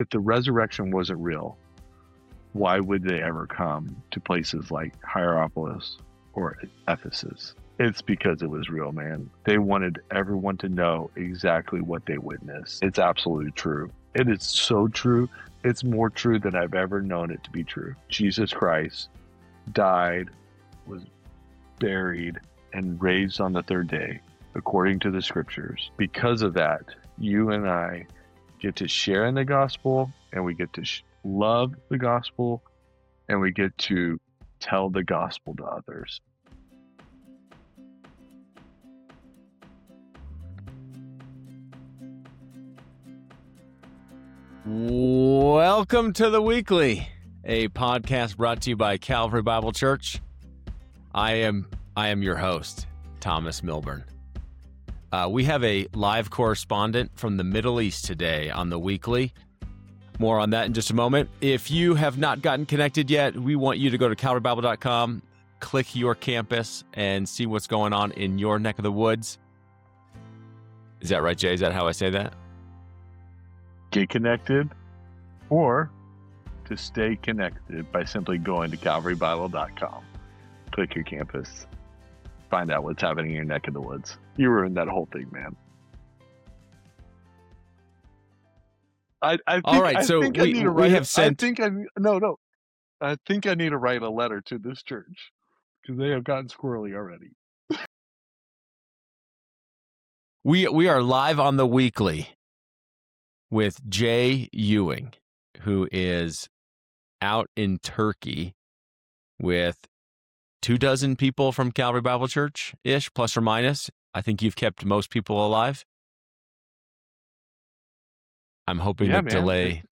a thoughtful conversation through John 11, the story of Lazarus.